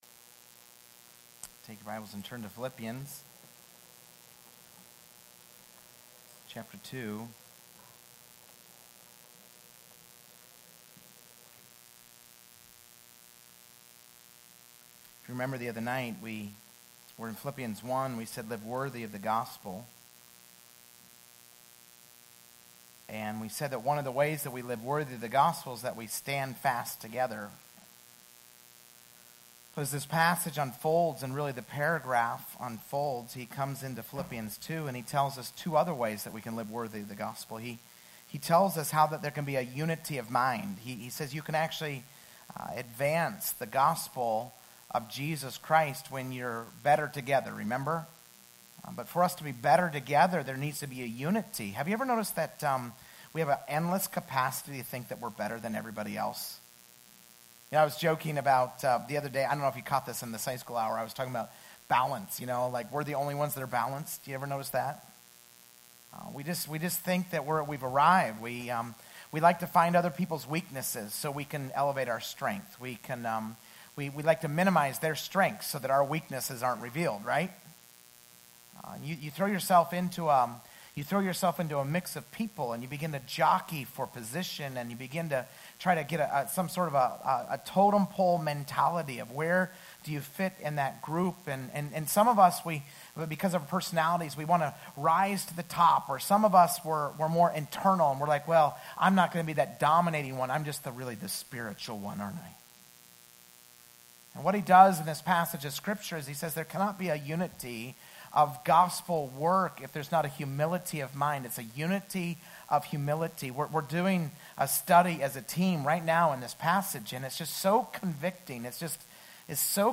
Sermons from visiting guests or other speakers